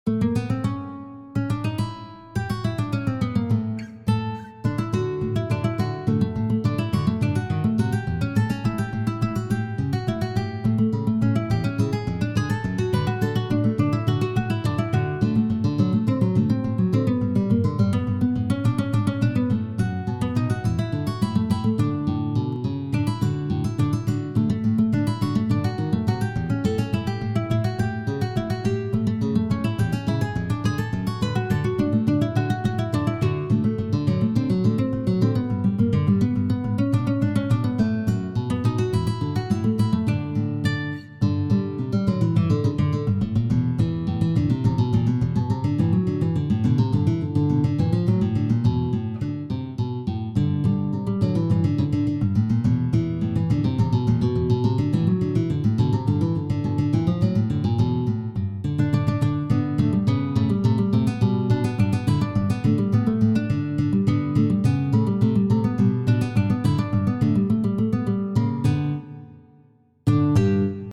Arrangements for solo guitar of the
and merry-go-rounds at amusement parks)